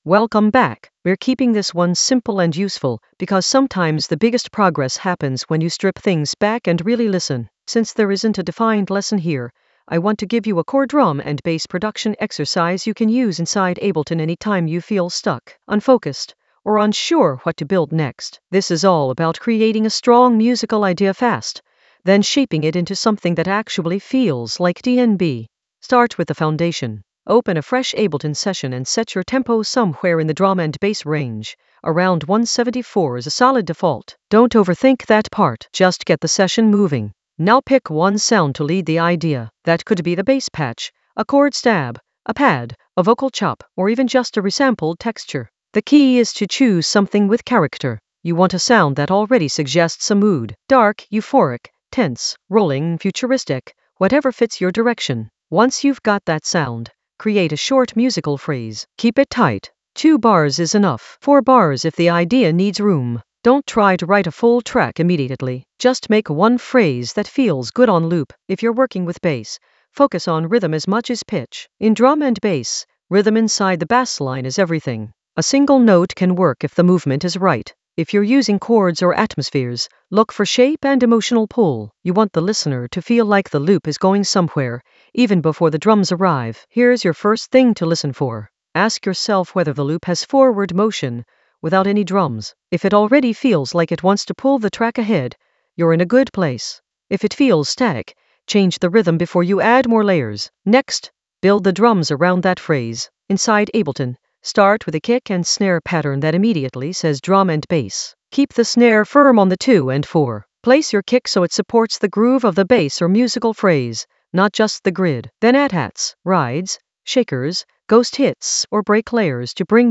An AI-generated beginner Ableton lesson focused on Top Buzz atmos and fx in the FX area of drum and bass production.
Narrated lesson audio
The voice track includes the tutorial plus extra teacher commentary.